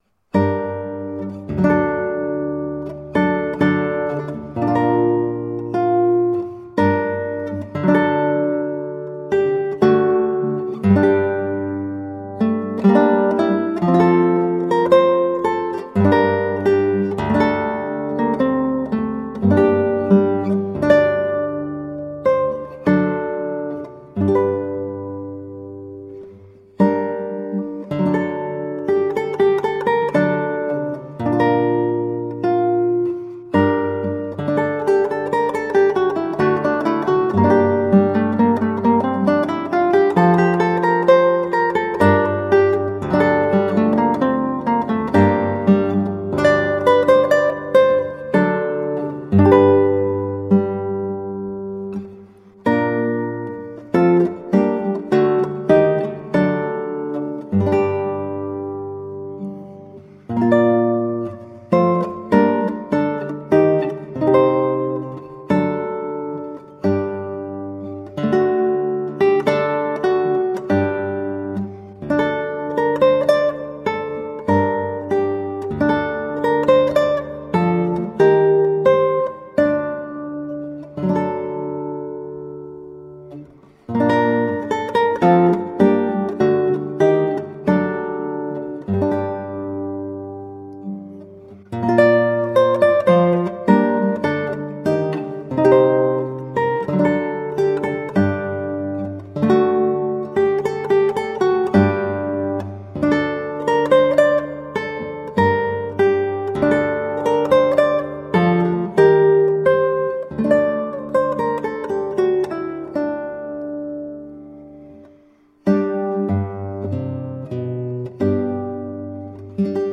Colorful classical guitar.
played on 8-string guitar